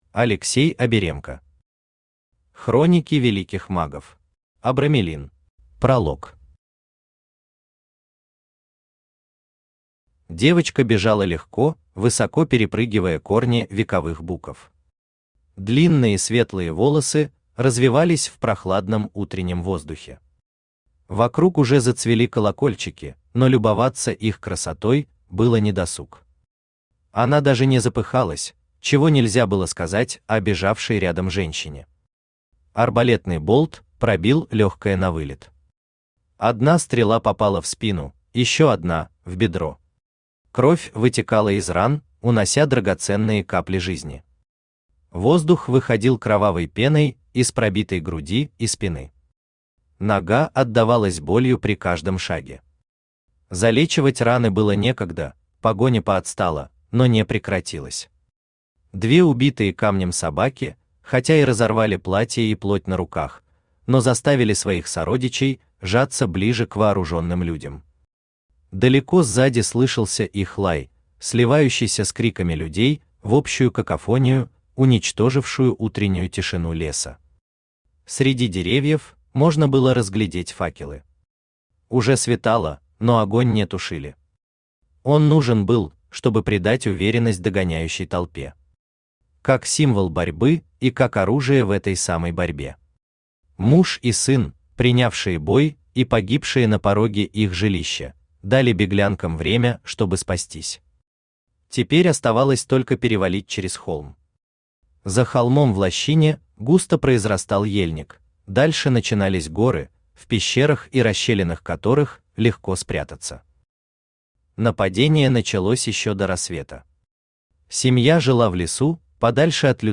Аудиокнига Хроники Великих Магов. Абрамелин | Библиотека аудиокниг